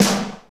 SNR F T S05R.wav